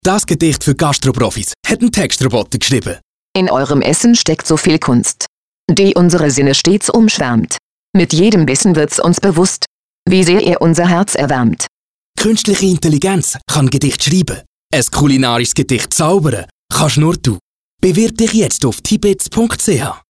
Die drei Radiospots und vier verschiedenen Online- und Plakatsujets zeigen auf humorvolle Art und Weise die Grenzen der künstlichen Intelligenz auf.
tibits_Radio_Gedicht.mp3